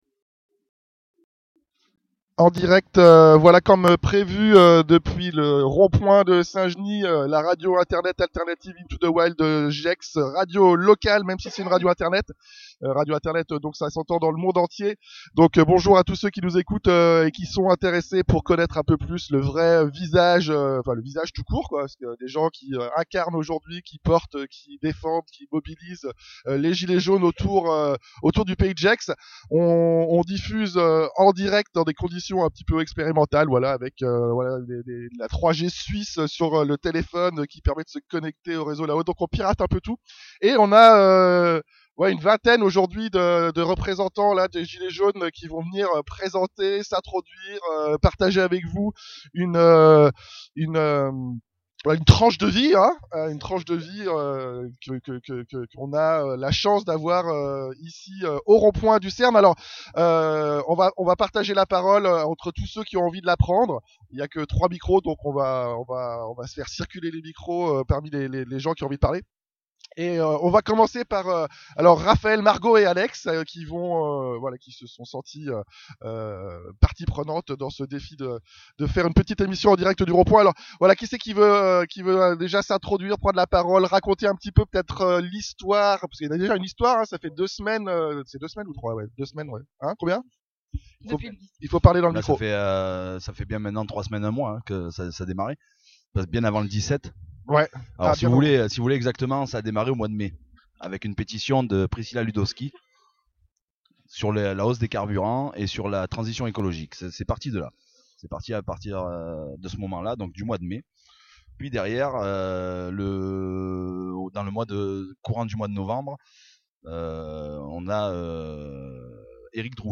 Enregistrement de l'émission spéciale Gilets Jaunes Gessiens en direct du rondpoint du CERN de St Genis le Mercredi 5 Décembre 2018. Table ronde et débats en public avec les acteurs locaux du mouvement. Après 3 semaines d'existence, la parole est donnée au groupe local pour expliquer le sens du mouvement et éviter les stéréotypes médiatiques.